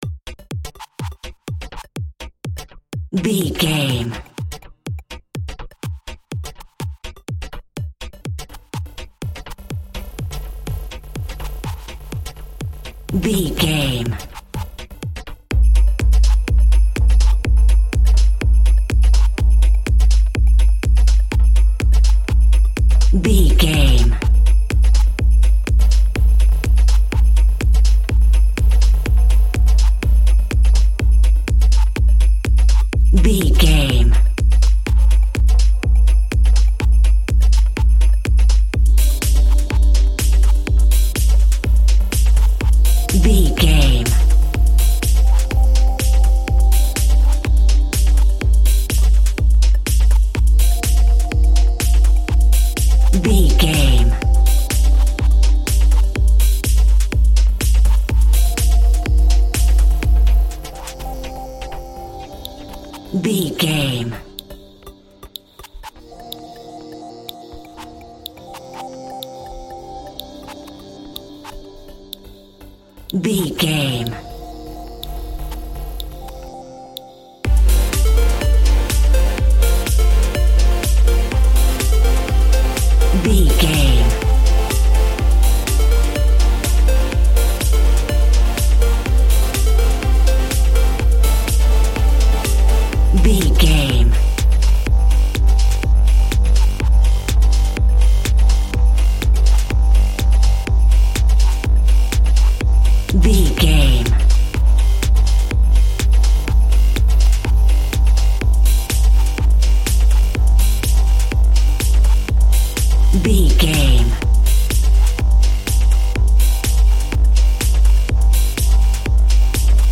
Aeolian/Minor
groovy
uplifting
driving
energetic
repetitive
synthesiser
drum machine
house
techno
trance
synth bass
upbeat